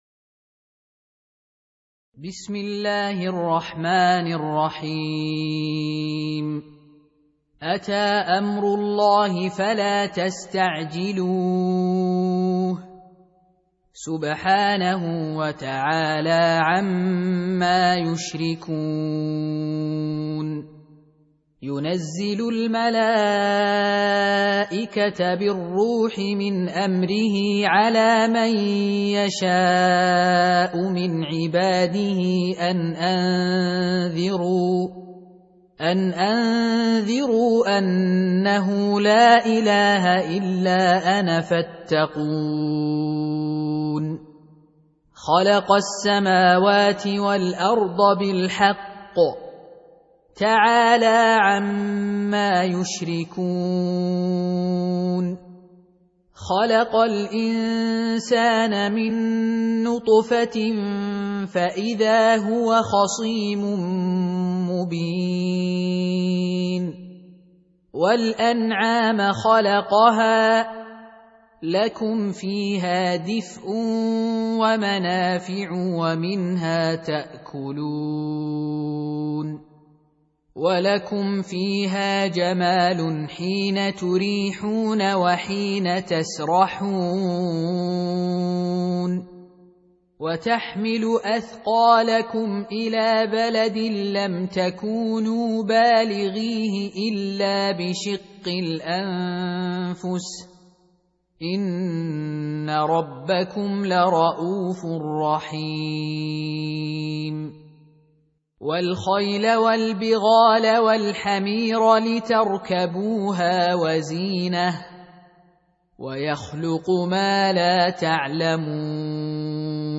Audio Quran Tarteel Recitation
Surah Repeating تكرار السورة Download Surah حمّل السورة Reciting Murattalah Audio for 16. Surah An-Nahl سورة النحل N.B *Surah Includes Al-Basmalah Reciters Sequents تتابع التلاوات Reciters Repeats تكرار التلاوات